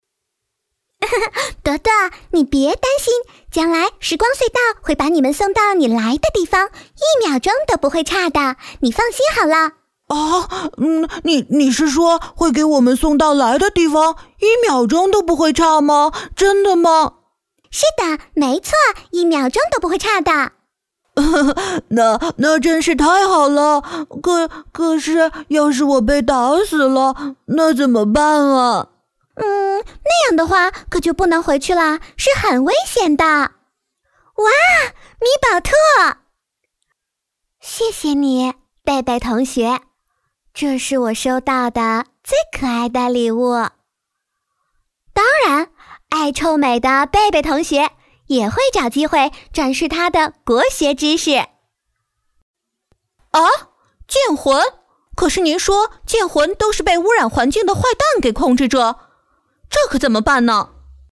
标签： 温情
配音风格： 稳重 温情 自然 知性 活力 轻快 磁性